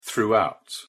pronunciation_en_throughout.mp3